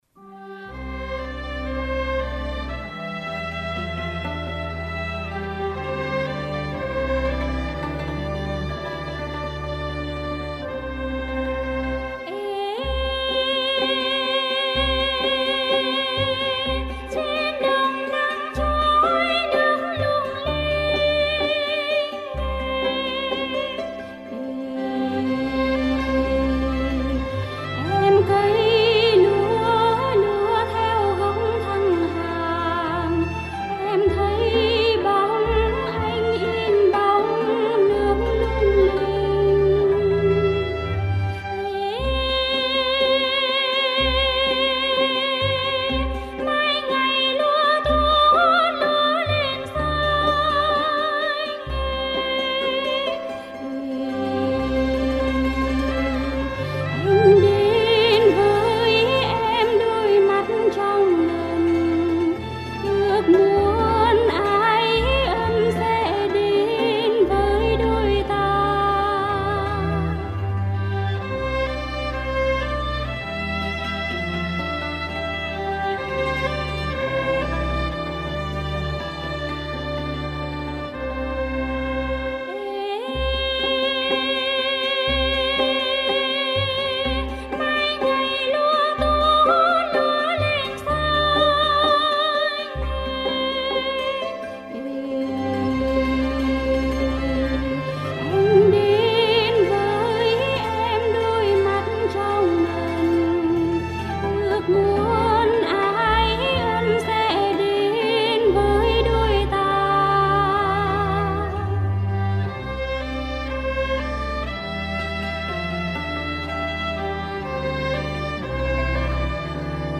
Dân ca Phù Lá lời cổ